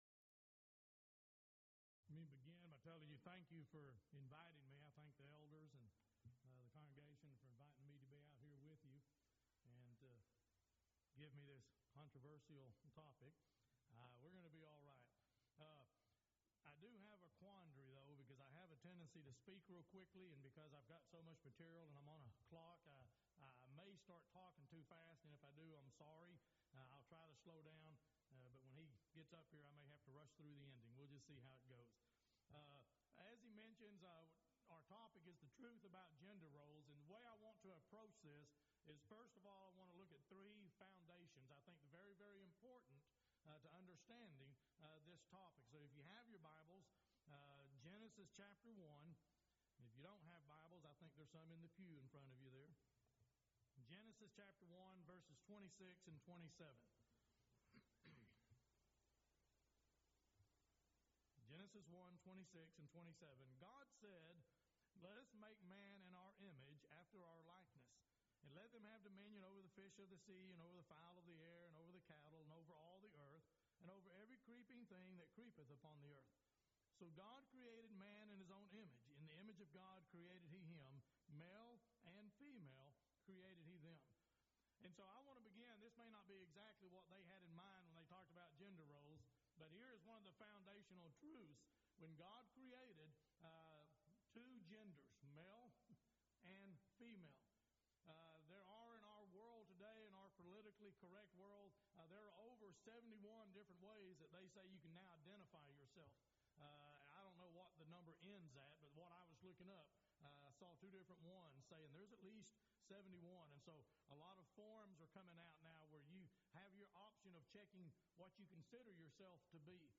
Event: 2nd Annual Colleyville Lectures
lecture